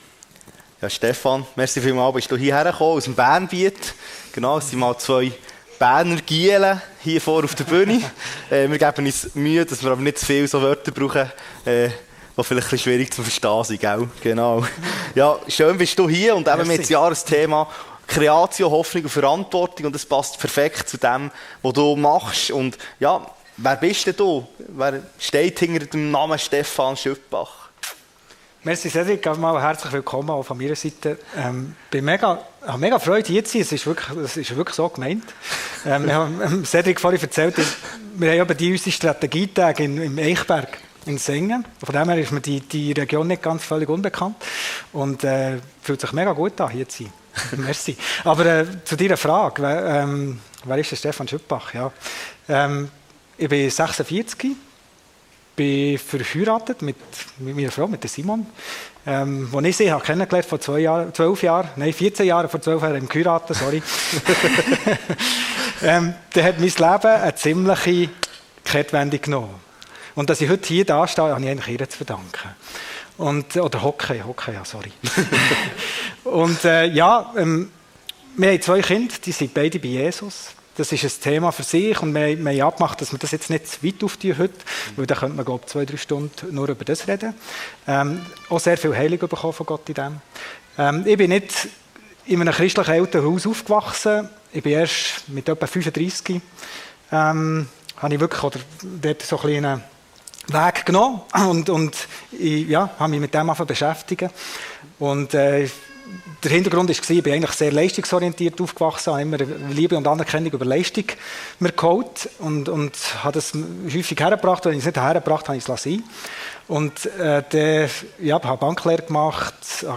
seetal chile Talk